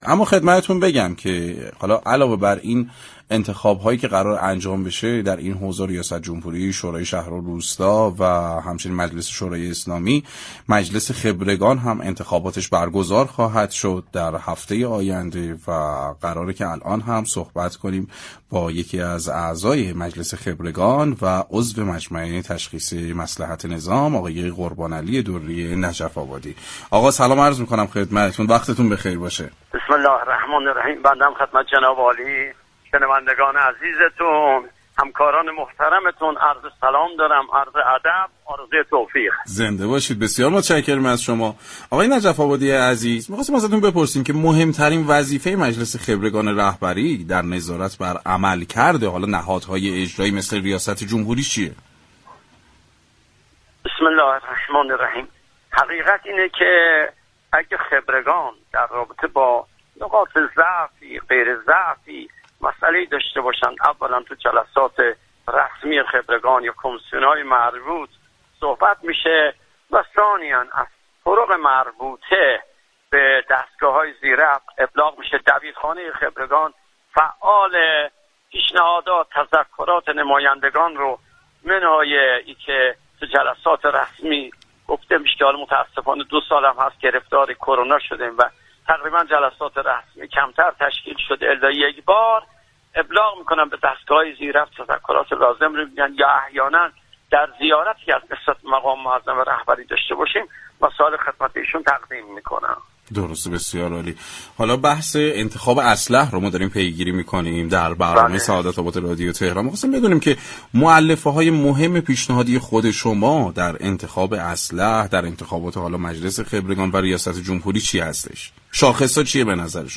به گزارش پایگاه اطلاع رسانی رادیو تهران همزمان با انتخابات ریاست جمهوری و شوراهای شهر و روستا، انتخابات میاندوره ای مجلس خبرگان رهبری هم برگزار می شود در همین راستا برنامه سعادت آباد رادیو تهران با حجت الاسلام قربانعلی دری نجف آبادی عضو مجمع تشخیص مصلحت نظام و مجلس خبرگان رهبری گفتگو كرد.